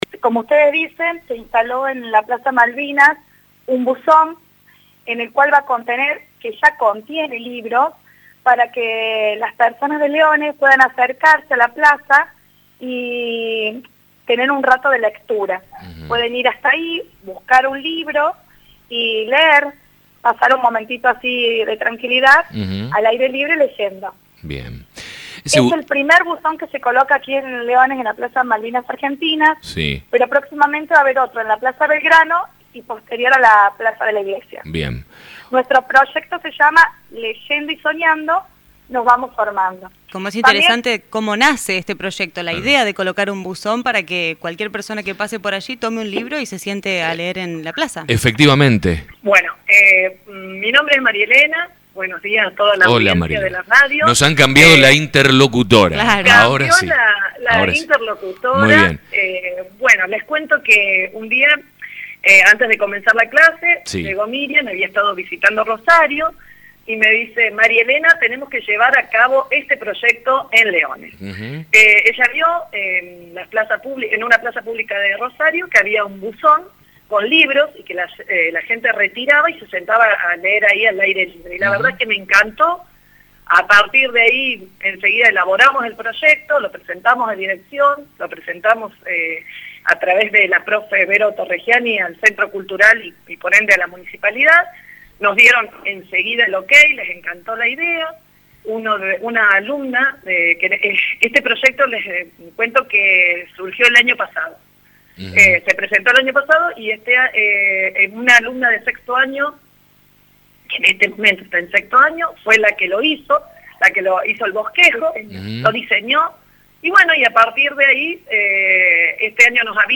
En conversaciones con La Mañana